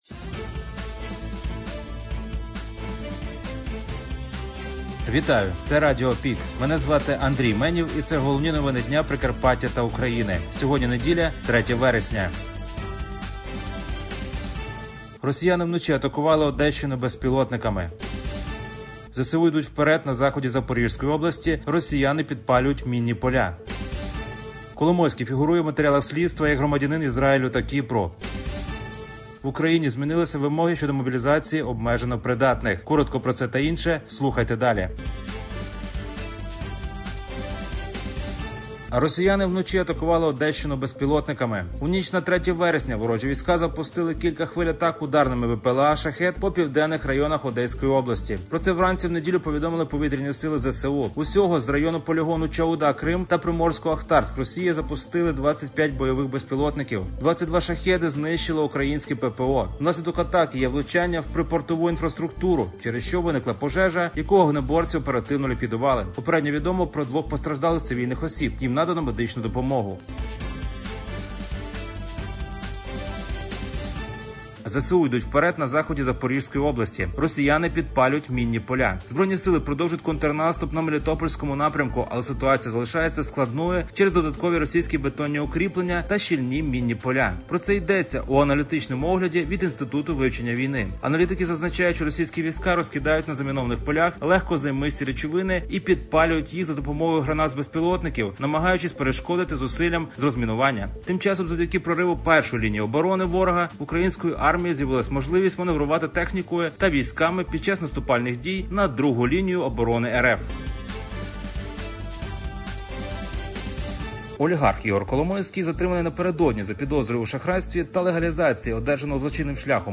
Пропонуємо вам актуальне за день – у радіоформаті.